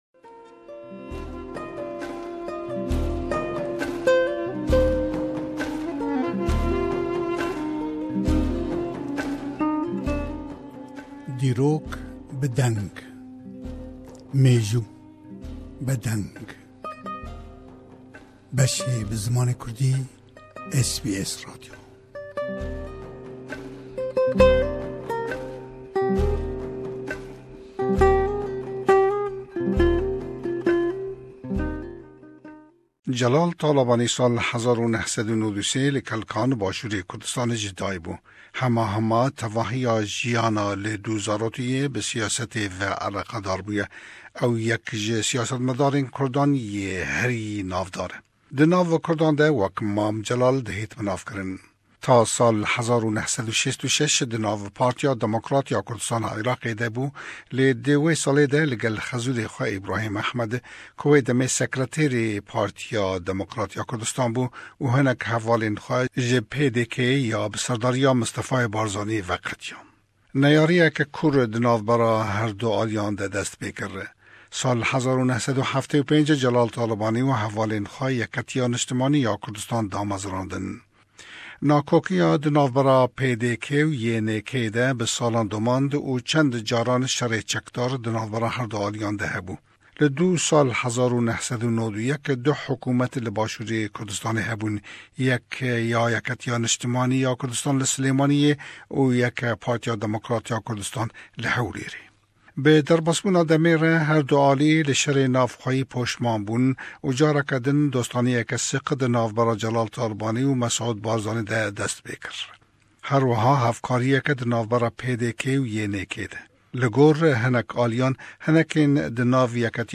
Le Arşîv: Gutarî Mam Celal le Sydney le 2003
Beşêk le gutarî Mam Celal le katî serdanî bo Sydney le sallî 2003, paş ruxandinî rijêmî Sedam Husên le Êraq.